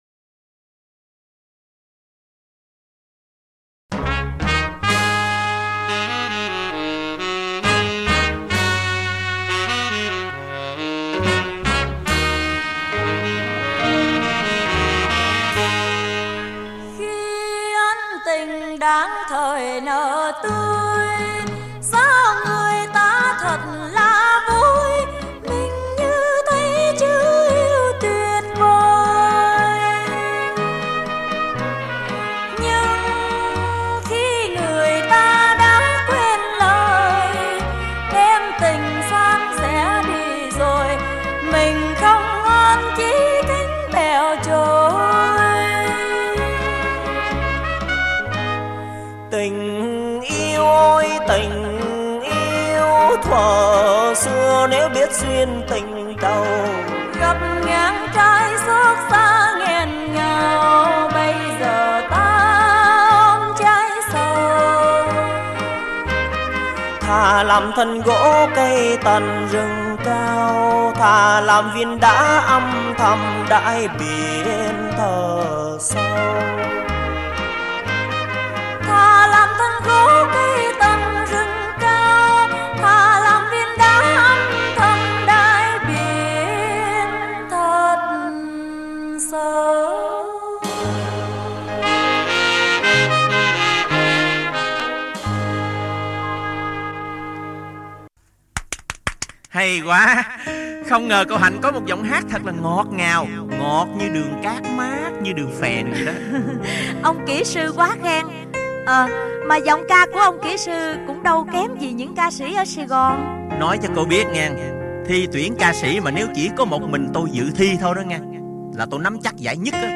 Thể loại: Cải Lương https